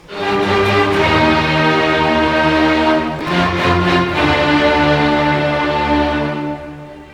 Dat maakte het voor de Duitsers nog een graadje erger: het begin van de Vijfde Symfonie van Ludwig van Beethoven, een van de grootste Duitse kunstenaars ooit. pô-pô-pô-pom